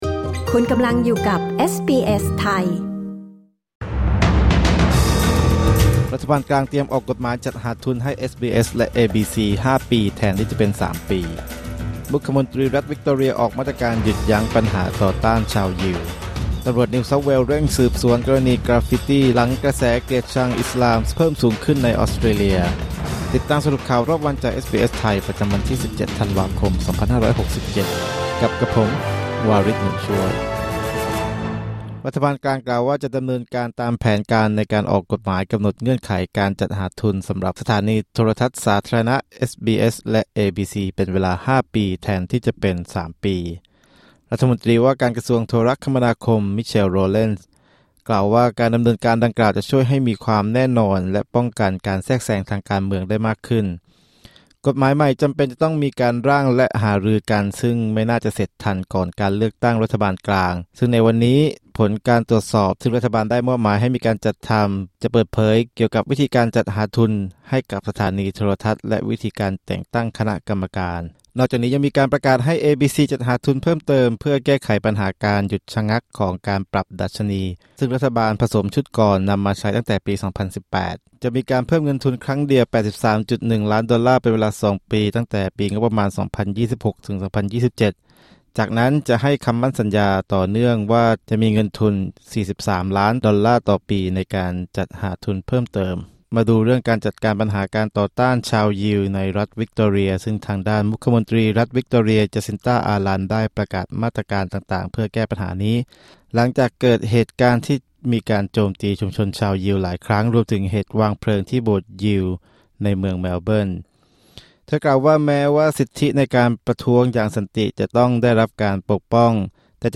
สรุปข่าวรอบวัน 17 ธันวาคม 2567